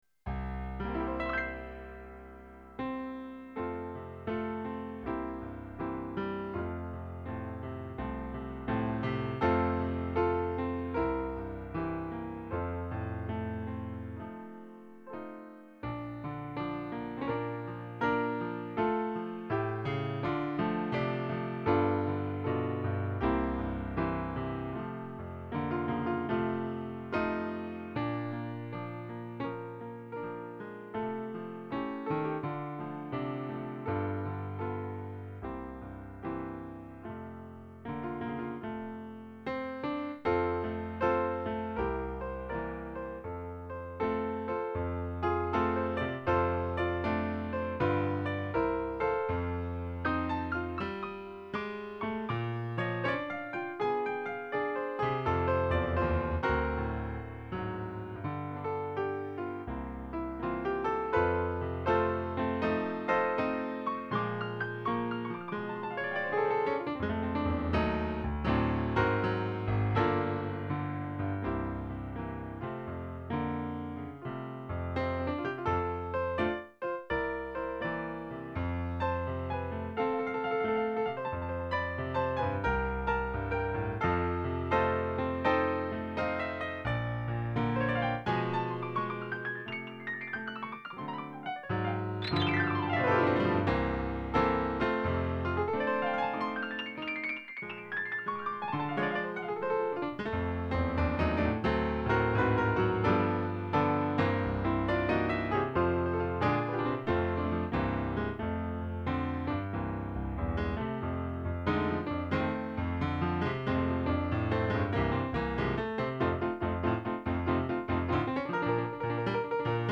Это джазовая импровизация с околоблюзовым уклоном на мелодию "В лесу родилась ёлочка" длительностью 4 мин. 43 с. Ввиду того, что не каждый из нас обладает выделенным и/или скоростным Интернетом, выкладываю два варианта каждого трека - стереофонический с битрейтом 192 кБит/с и монофонический (96 кБит/с соответственно). Записано с использованием звуковой карты Sound Blaster Live! 5.1 (ещё дышит, старушка) и профессиональной midi-клавиатуры M-Audio ProKeys-88. Мой любимый "Ред Окчоуба" расстроен, да и микрофонов хороших нет, посему писать пришлось со звукового выхода ProKeys. На данном "сингле" из инструментов фигурирует исключительно голое фортепиано:
yolo4ka-2008_mono.mp3